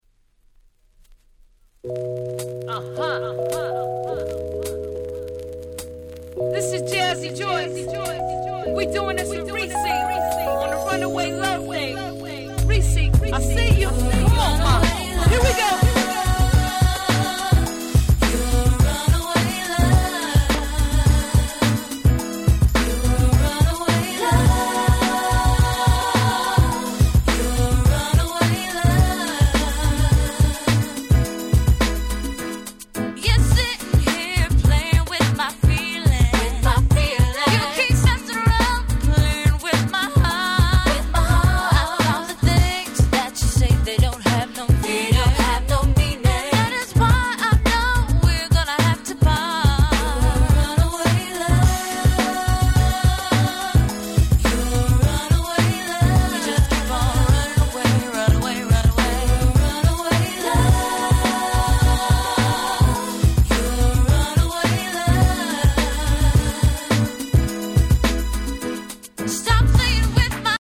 00' Nice Cover R&B !!
00's キャッチー系